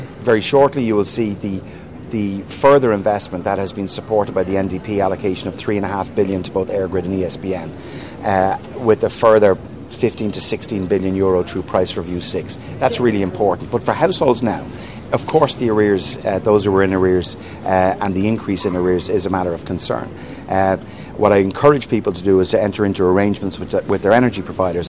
Energy Minister Darragh O’Brien says they need to take a longer term view……………..~